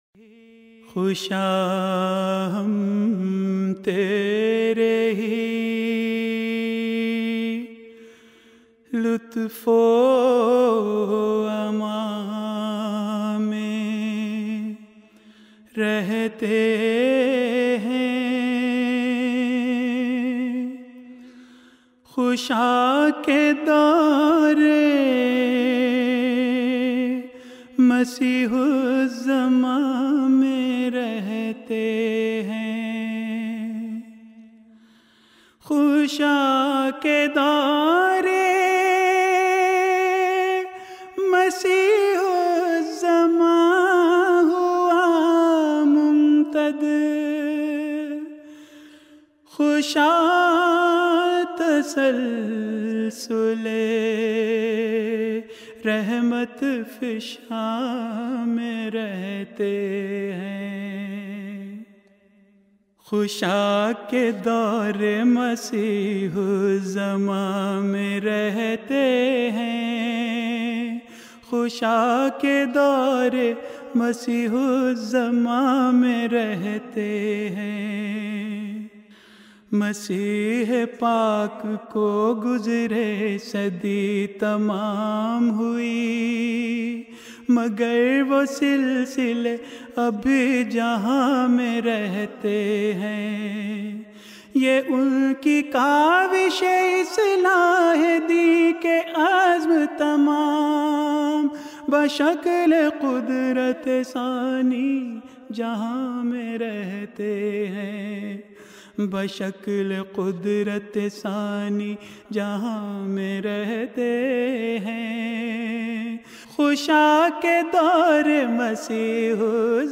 Urdu Poems
جلسہ سالانہ یوکے ۲۰۱۷ء Jalsa Salana UK 2017